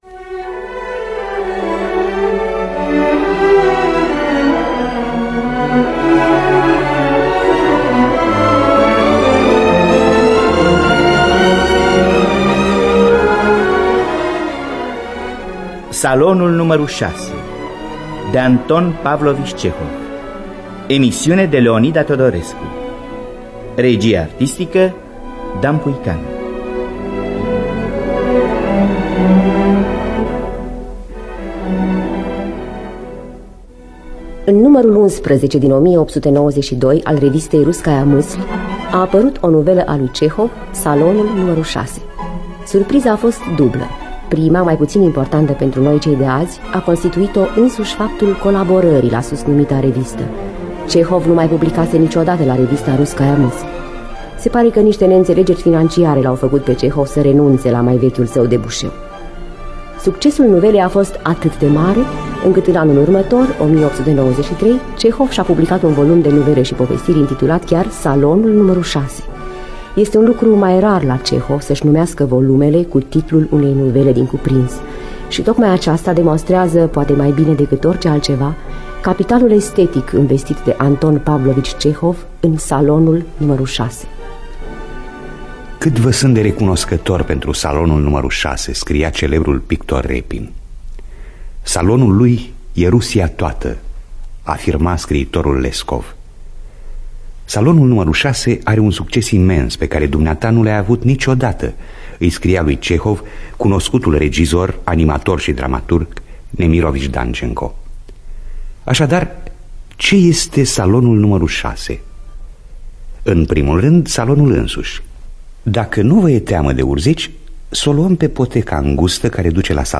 Scenariu radiofonic